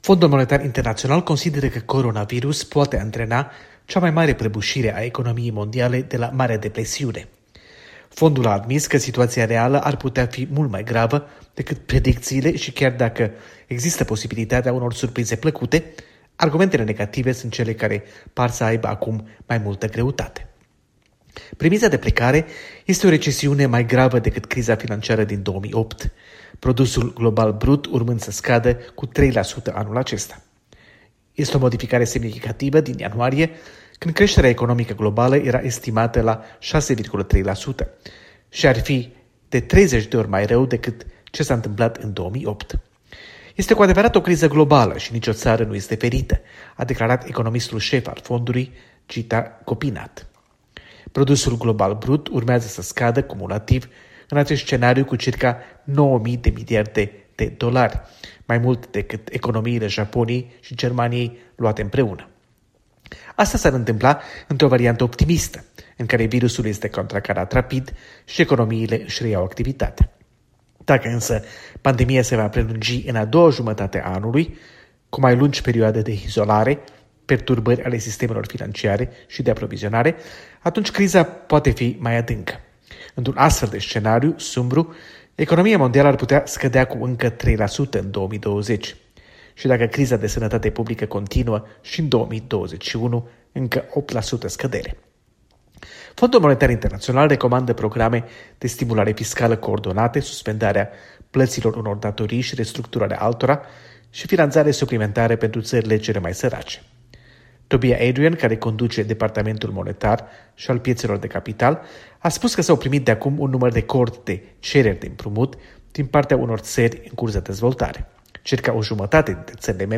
Corespondență de la Washington: FMI și starea economiei mondiale